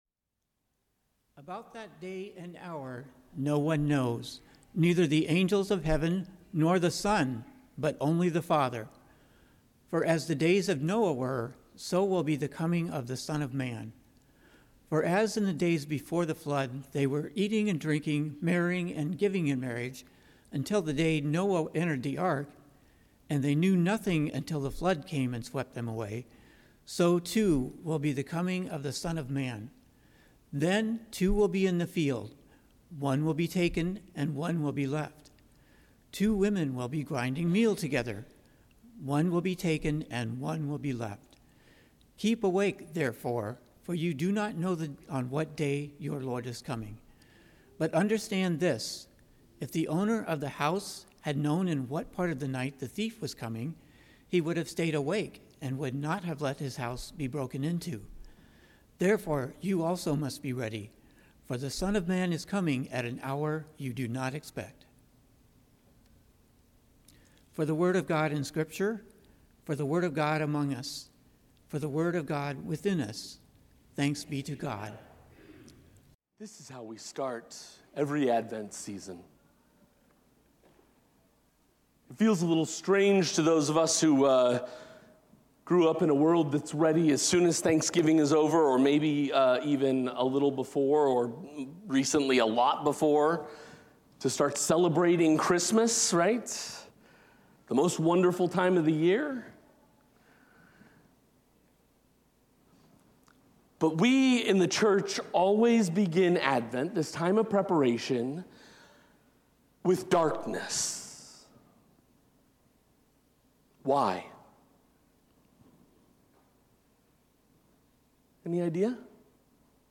Sermons | Broadway United Methodist Church